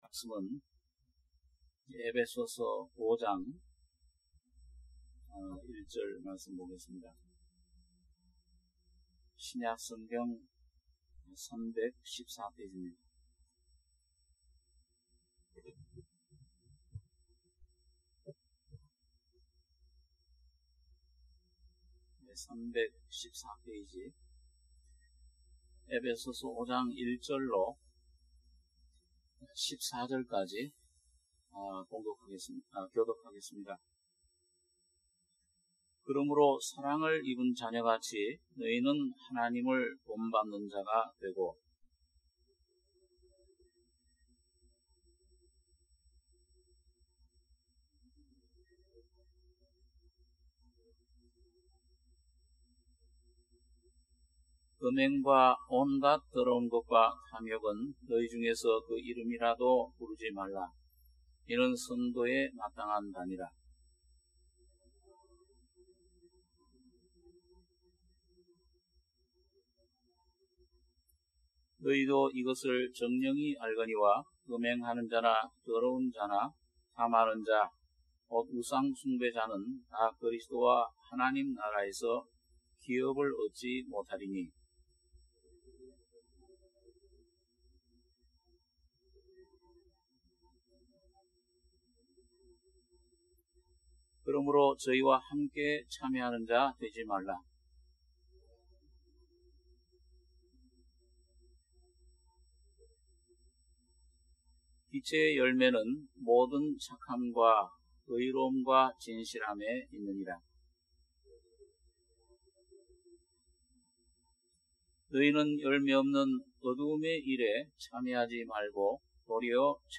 주일예배 - 에베소서 5장 1절~15절(주일오후)